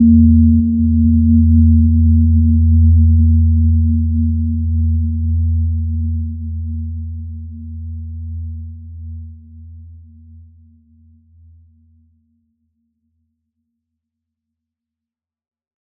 Gentle-Metallic-4-E2-mf.wav